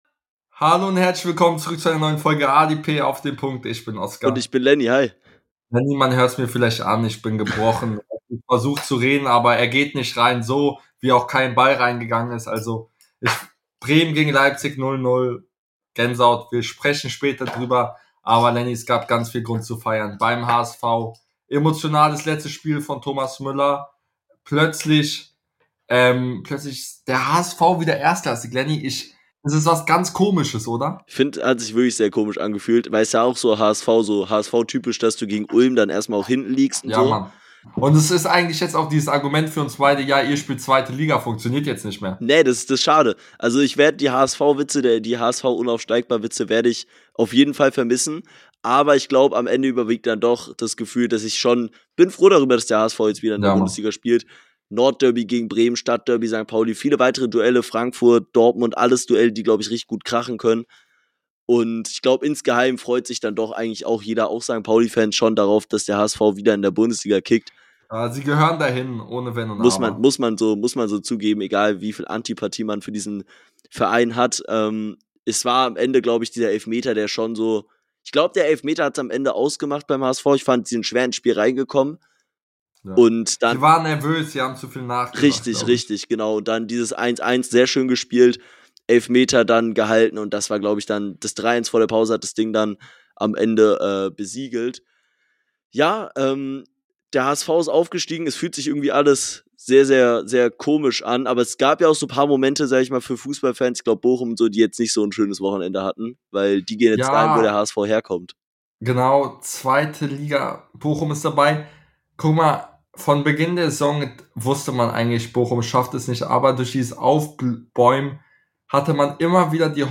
In der heutigen Folge sprechen die beiden Hosts über den Aufstiegs des HSV, Müllers letztes Heimspiel , den Kampf um Platz 3 in Liga 2 und der Champions League und vieles mehr